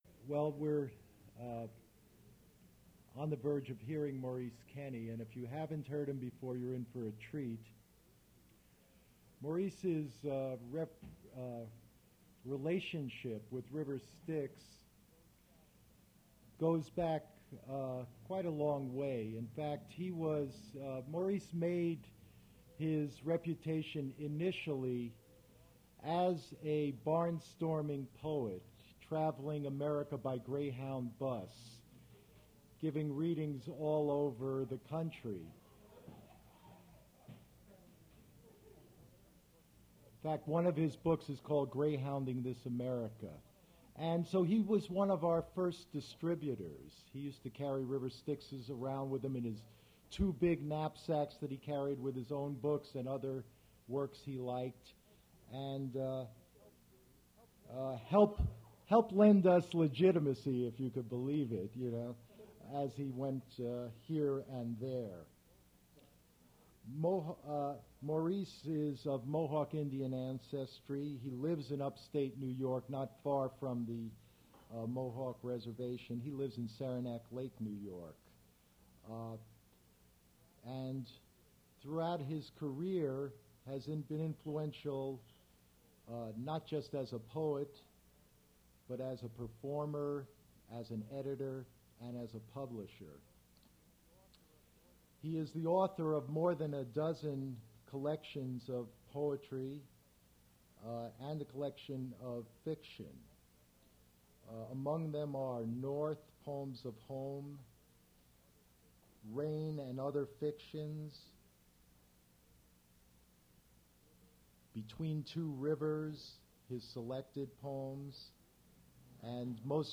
Poetry reading featuring Maurice Kenny
Attributes Attribute Name Values Description Maurice Kenny reading his poetry at Duff's Restaurant.
mp3 edited access file was created from unedited access file which was sourced from preservation WAV file that was generated from original audio cassette.
recording starts from introduction, there is a period of blank at 45:55